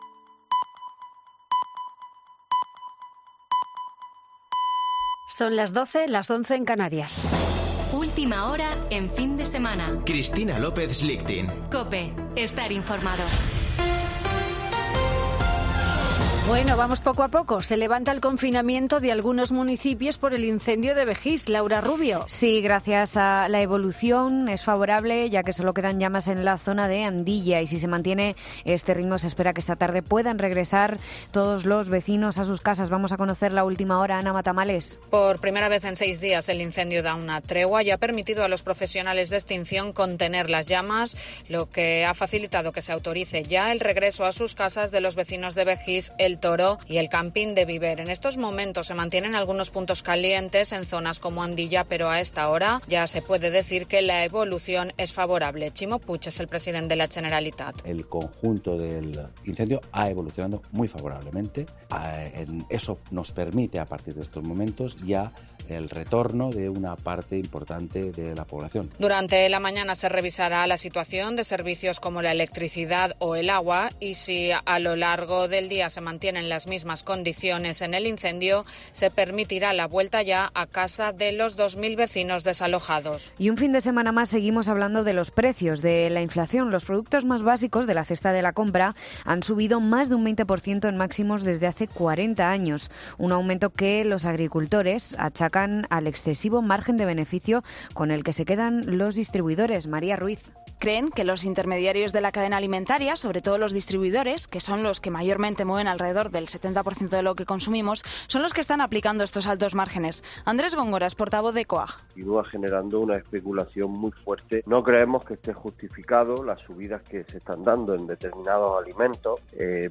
Boletín de noticias de COPE del 20 de agosto de 2022 a las 12.00 horas